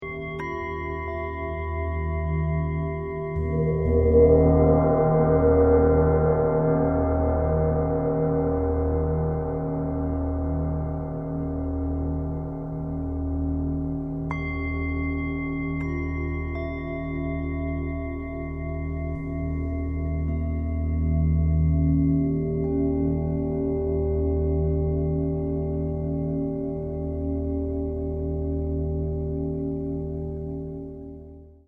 Listen to the dynamic resonance of the rounding sound.